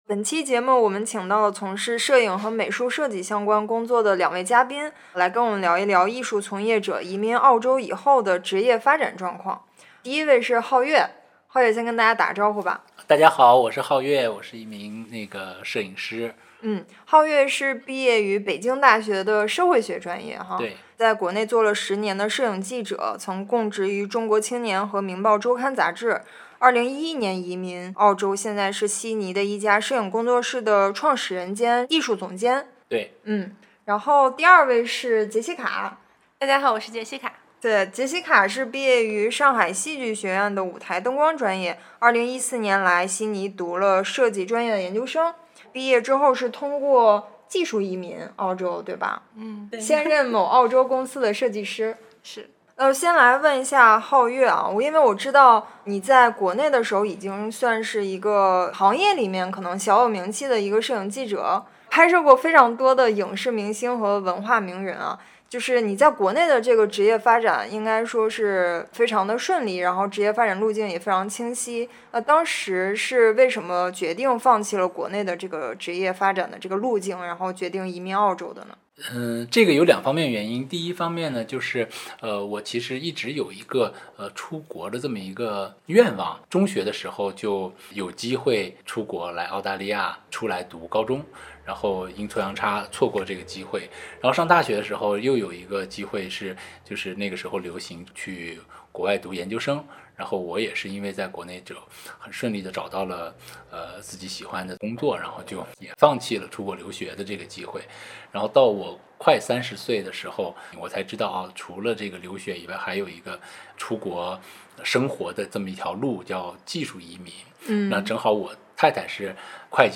艺术行业的从业者，例如摄影师、美术设计师、戏剧导演等，移民澳洲后难就业吗？今天，我们请到了两位分别在澳洲从事摄影和设计工作的嘉宾，来跟我们聊一聊他们移民澳洲后是如何找到第一份工作，以及如何规划自己职业发展的。